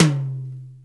Index of /90_sSampleCDs/Roland L-CD701/TOM_Real Toms 1/TOM_Ac.Toms 1
TOM CROSS 13.wav